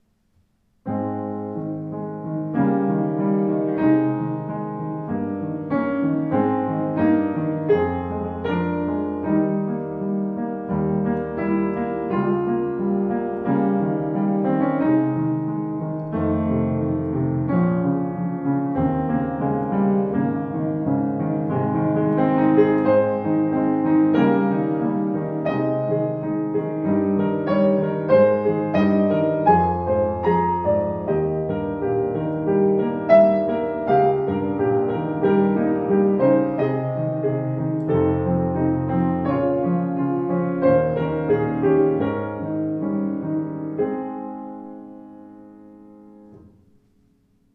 Warmer, ausgewogener Klang mit satten Bässen.